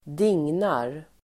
Ladda ner uttalet
Uttal: [²d'ing:nar]
dignar.mp3